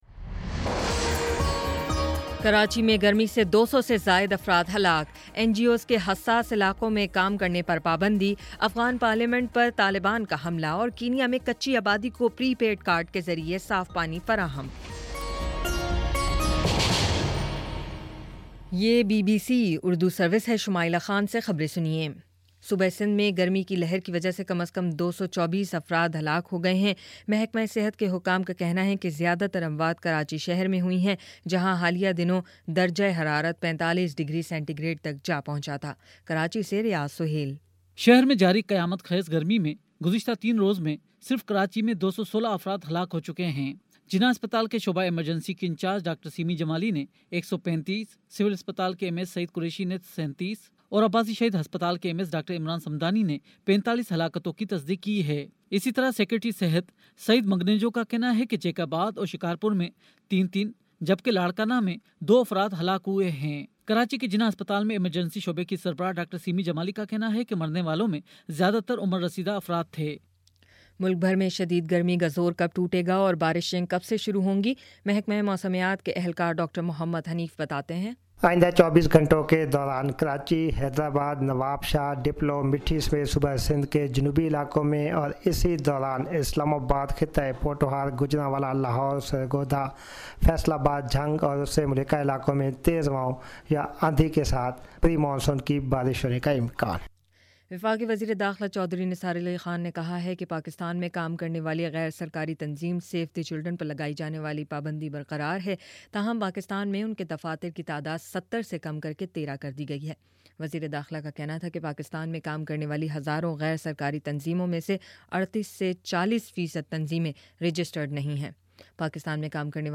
جون 22: شام پانچ بجے کا نیوز بُلیٹن